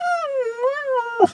cat_2_meow_long_07.wav